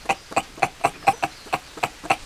Skull of a mountain paca (Cuniculus taczanowskii), illustrating its enlarged cheek bones (at red arrow), which house resonating chambers for its vocalizations.
bark
bark.wav